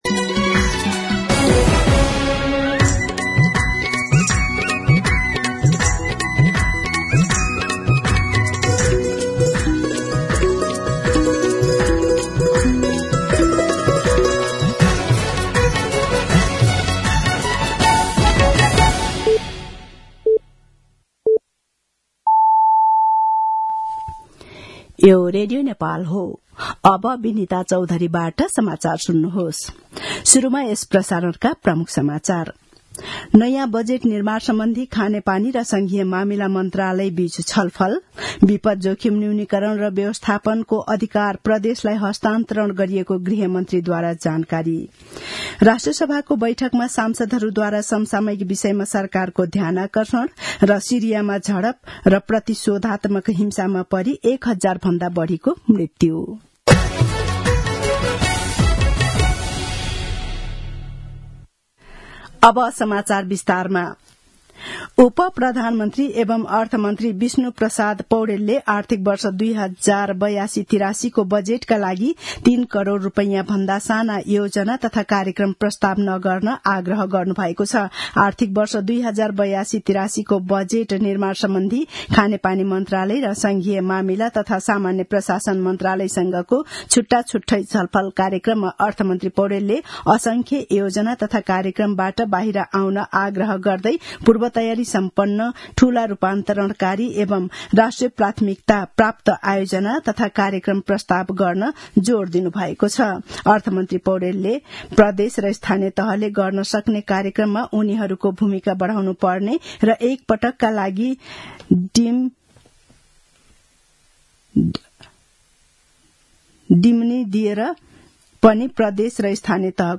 दिउँसो ३ बजेको नेपाली समाचार : २६ फागुन , २०८१